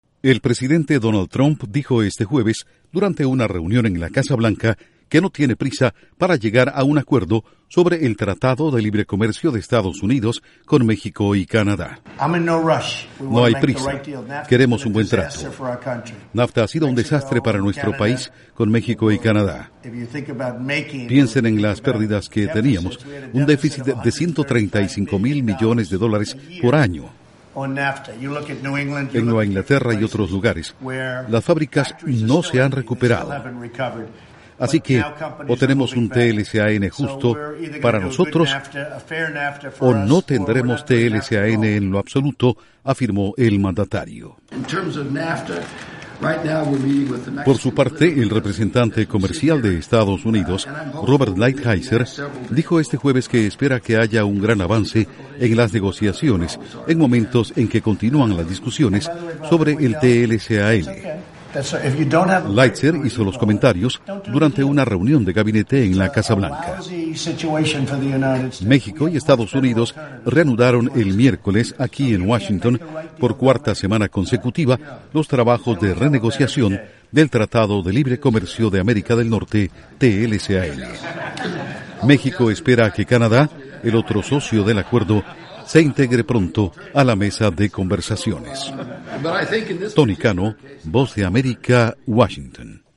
1 audio de Donald Trump/Presidente de EE.UU. 1 audio de Robert Lighthizer/Representante Comercial de EE.UU.